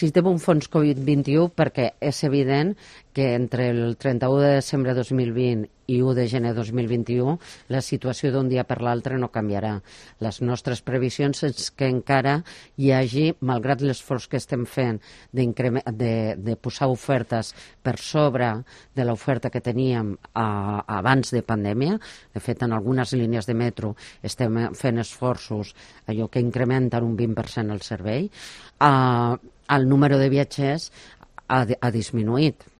La presidenta de TMB, Rosa Alarcón, explica los motivos del fondo de rescate covid